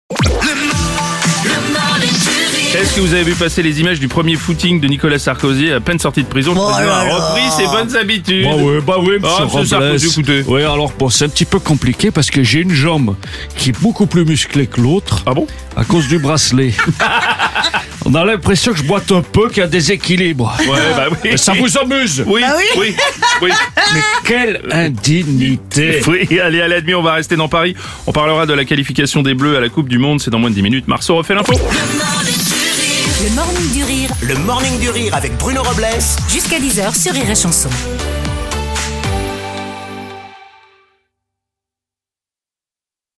L’imitateur